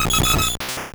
Cri de Dodrio dans Pokémon Or et Argent.